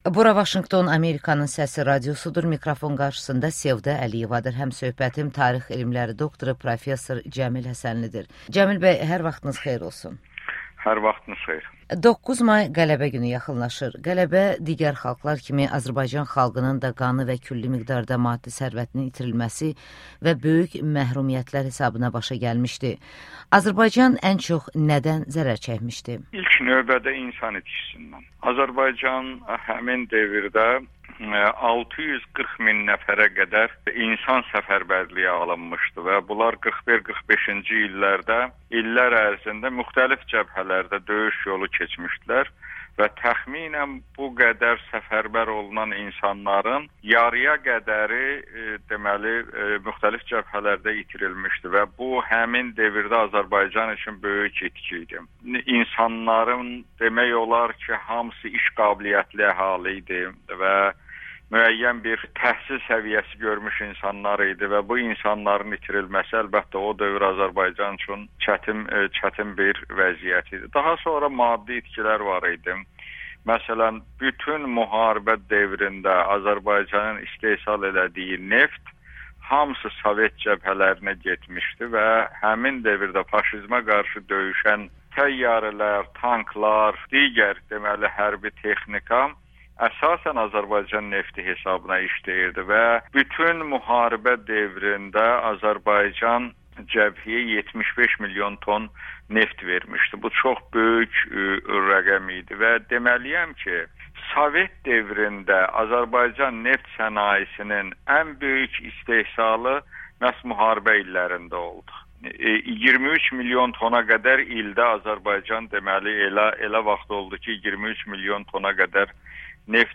Cəmil Həsənli ilə müsahibə